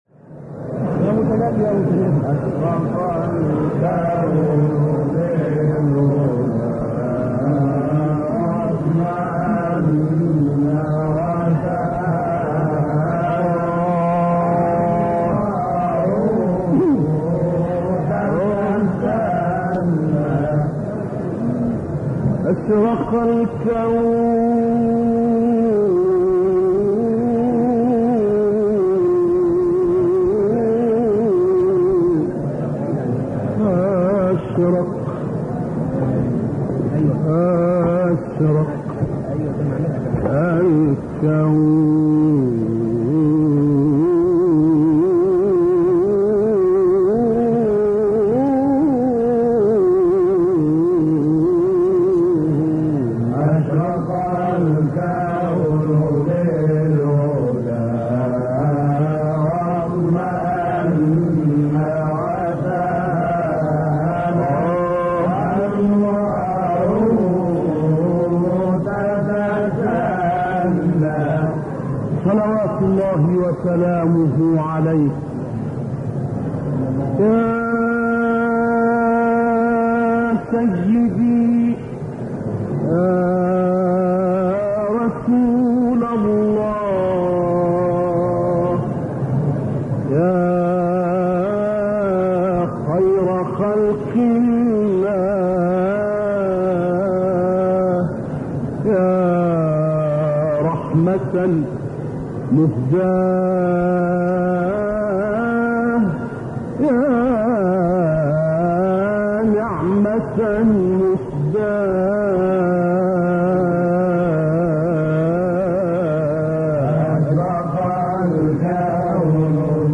نوار ابتهال 48 سال بعد از ضبط، پیدا شده است. دریافت این ابتهال در مسجد سیده زینب(س) شهر قاهره در سال 1968 میلادی اجرا شده است.